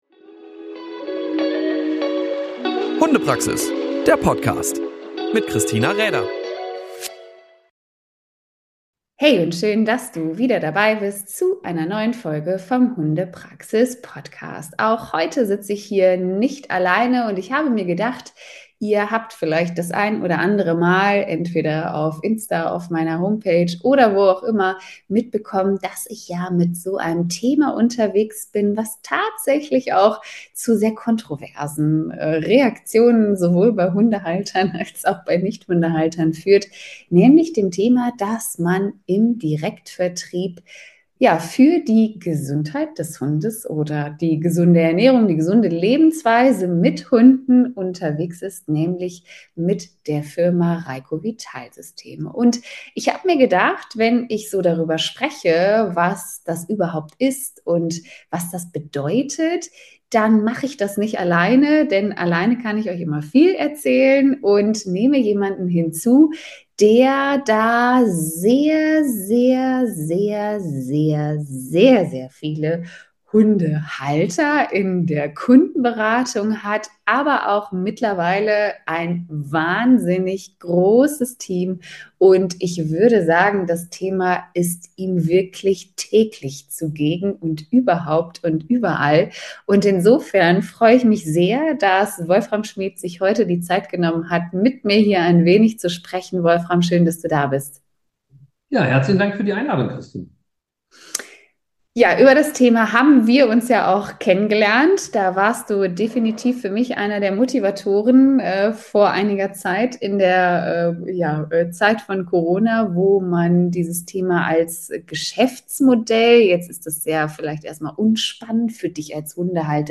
Was wir da so machen, warum das ein bisschen mehr ist, als nur einen Sack Futter loswerden wollen und warum dieses ganzheitliche Konzept nicht nur mich so überzeigt hat, hört ihr in diesem Interview!